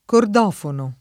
cordofono [ kord 0 fono ] s. m. (mus.)